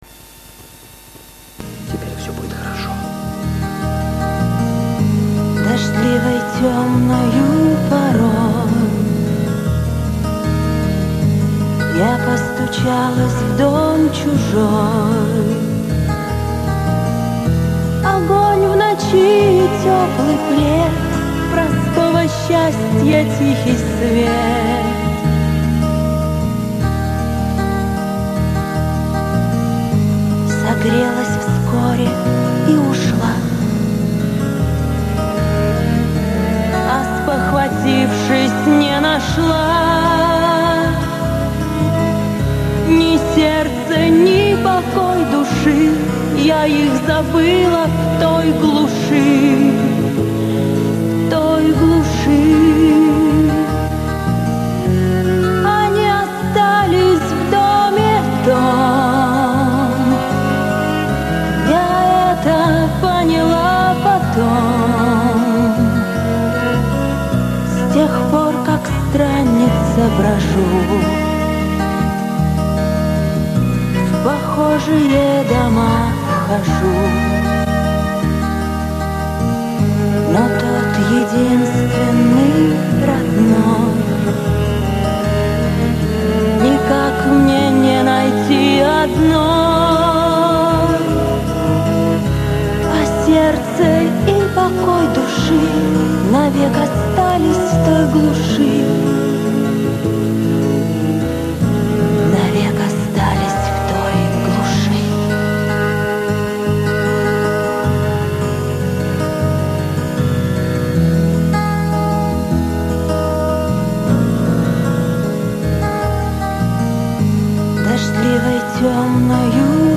Вот только такая версия (из фильма вырезано)